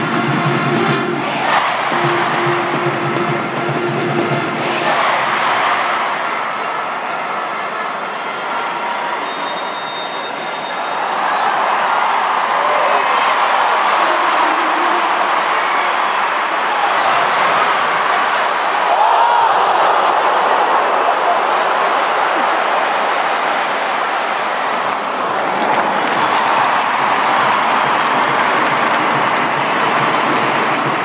kick off at England v Germany